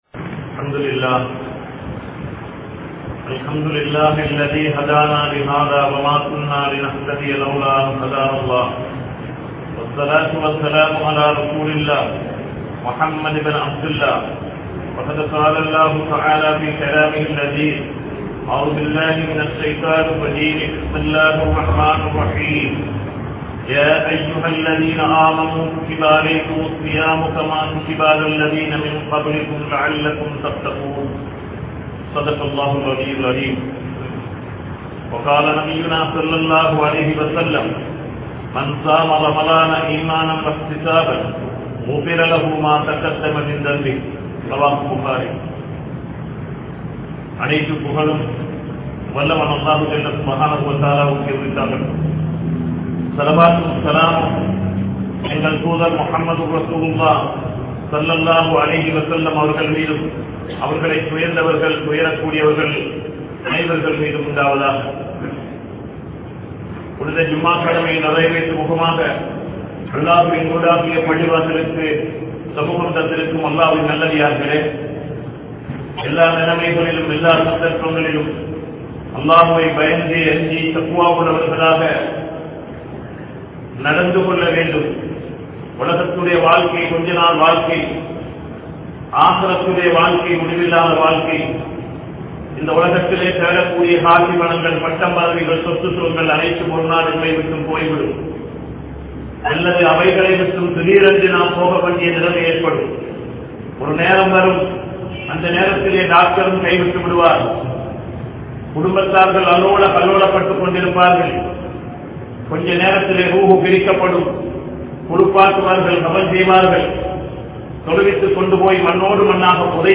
Ramalaanin Sirappuhal | Audio Bayans | All Ceylon Muslim Youth Community | Addalaichenai